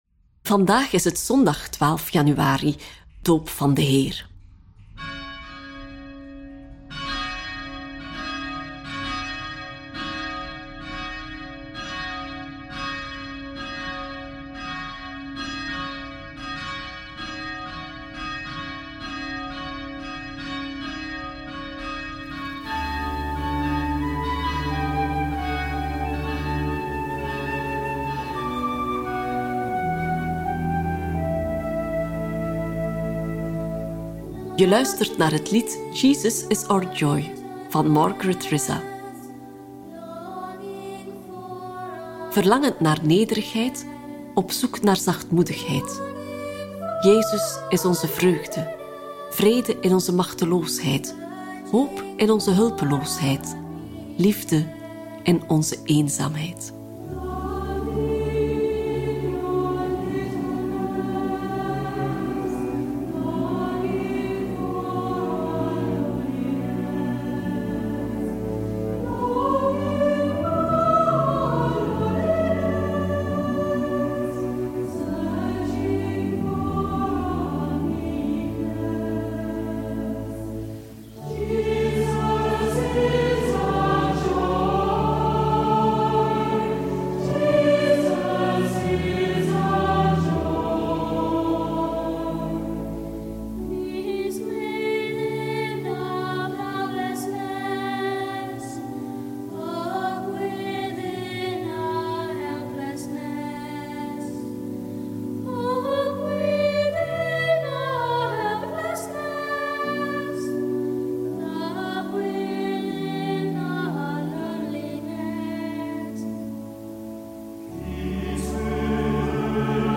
De muzikale omlijsting, overwegingen y begeleidende vragen helpen je om tot gebed te komen.